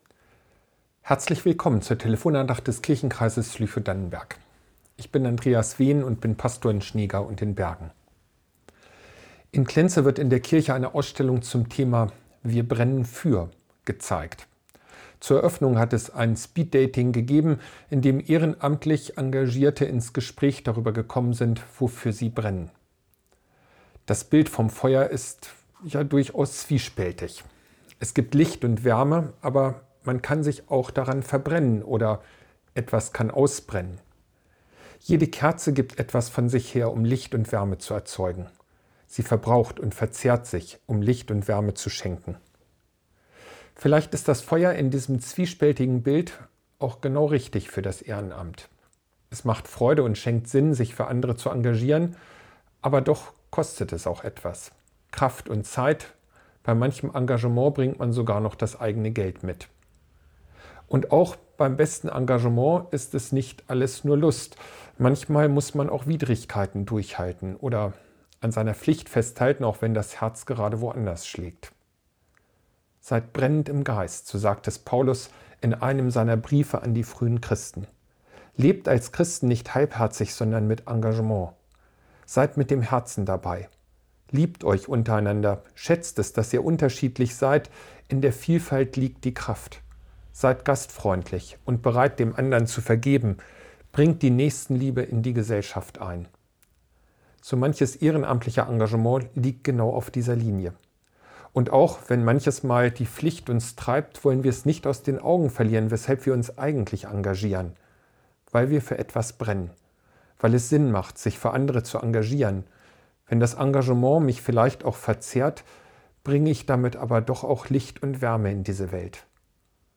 Wofür brennst du ~ Telefon-Andachten des ev.-luth. Kirchenkreises Lüchow-Dannenberg Podcast